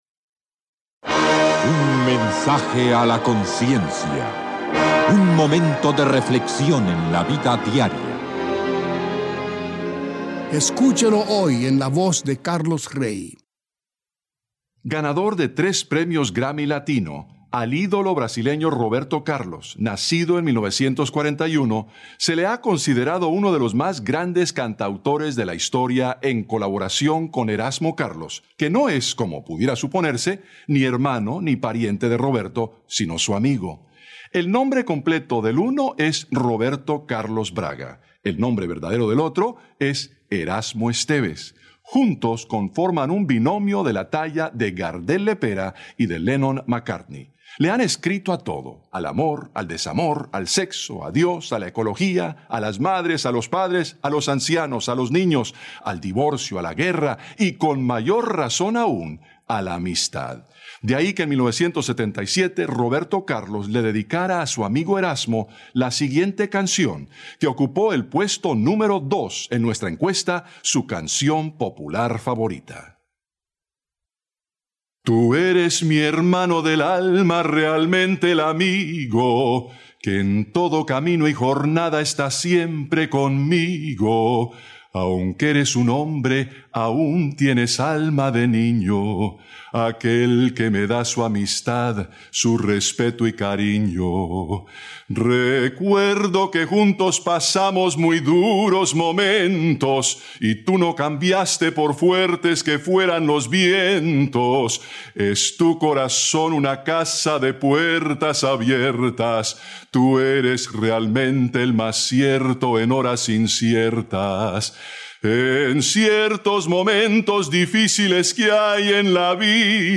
Canción cantada